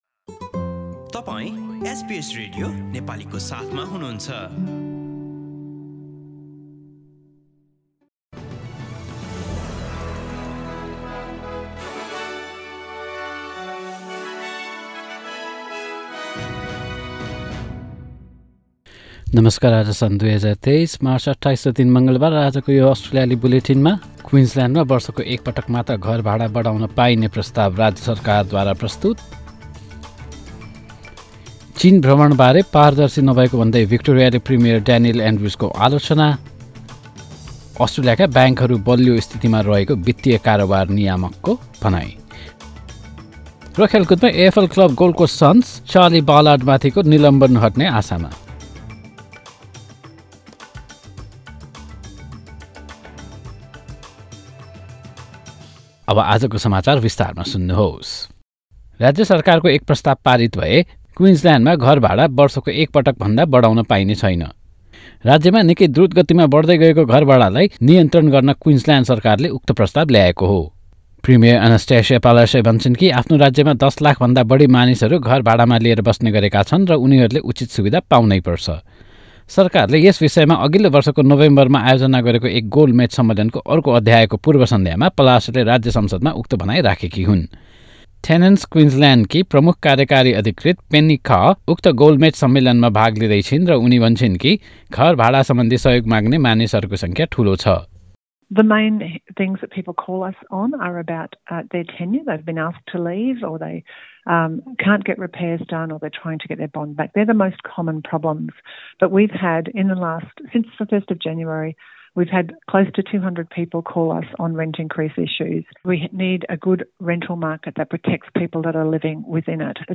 Listen to the latest top news from Australia in Nepali. In this bulletin: Queensland tenants will only see their rent raised once a year, rather than every six months, Victorian Premier Daniel Andrews faces criticism over a lack of transparency on his visit to China and Australia's banks are in a strong position to protect against turbulent conditions overseas, according to the banking regulatory authority.